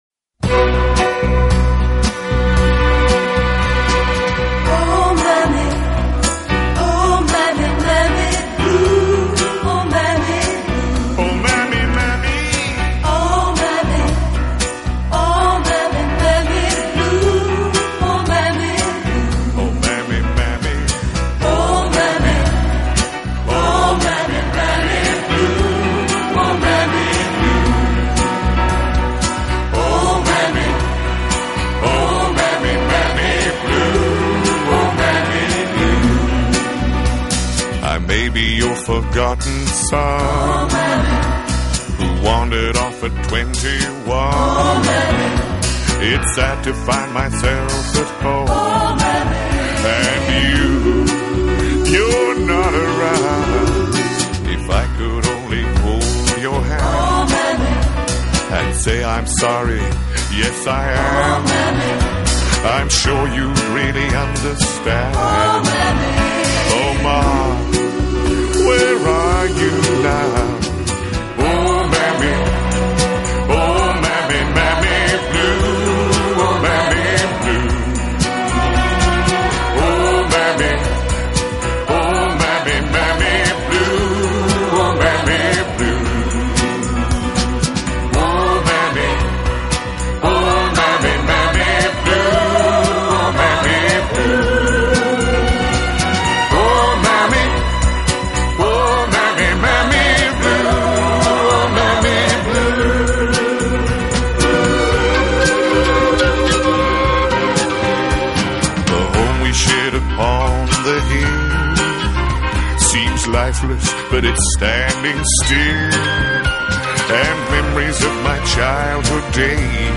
温暖，没有半点乔情或故作柔情，可又令您觉得很舒服，好亲切。